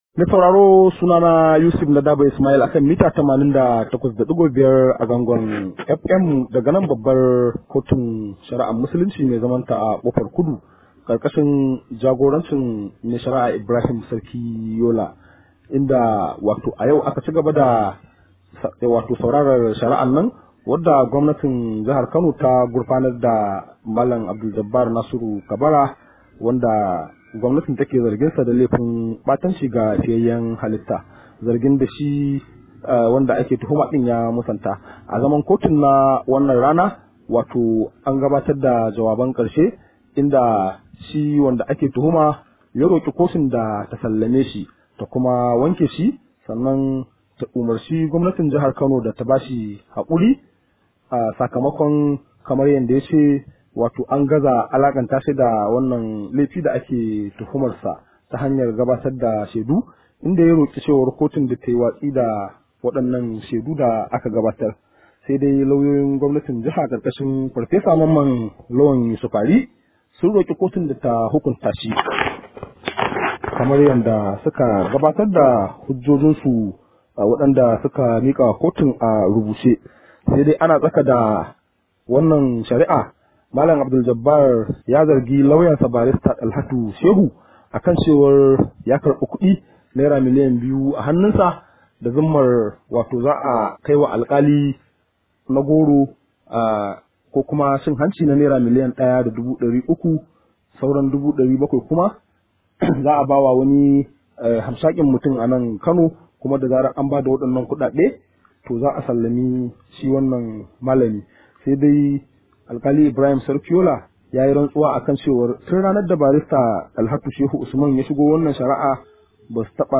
Akwai cikakken rahoton